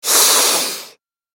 Звуки шипения кота
Злой звук шипящей кошки (агрессивная реакция)